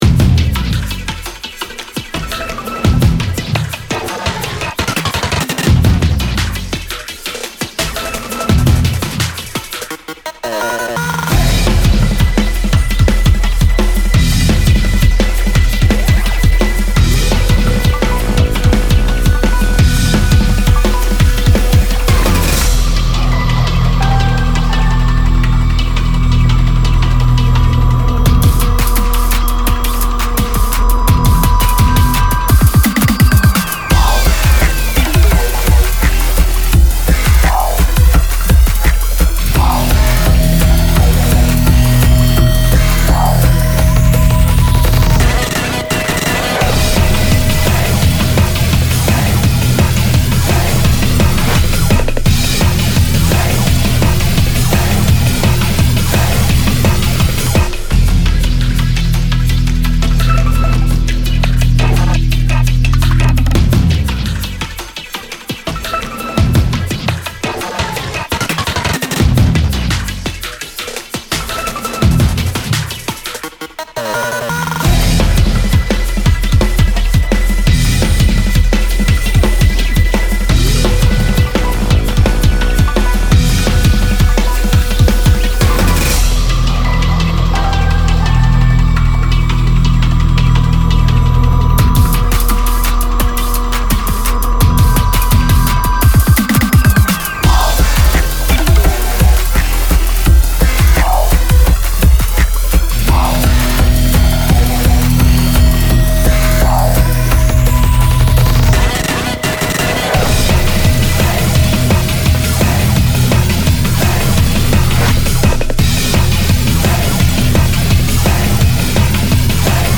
エレキギターとエレクトロニックなアレンジの練習がてら制作しました。